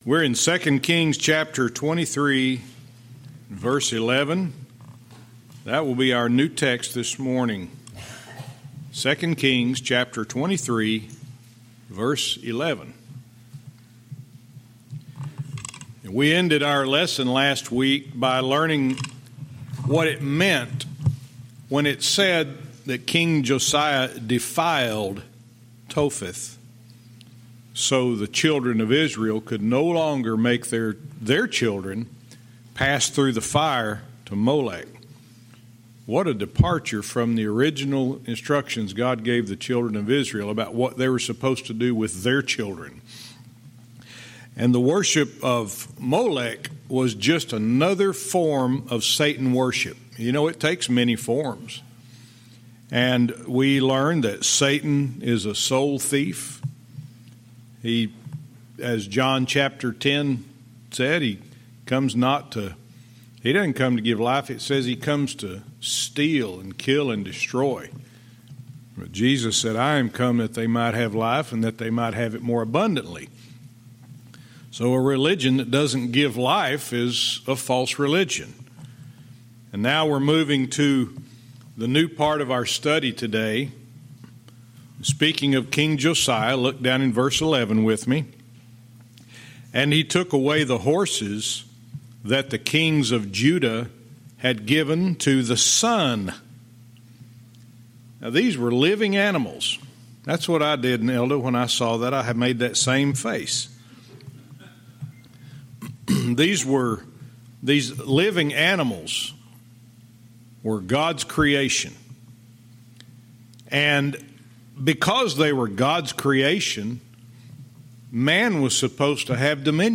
Verse by verse teaching - 2 Kings 23:11-13